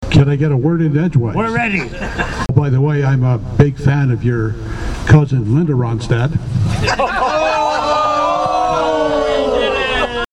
The Third Annual Arnprior Lions Jail and Bail had more comedy and drama than ever before, as the fundraiser nestled into the Giant Tiger Parking Lot Saturday.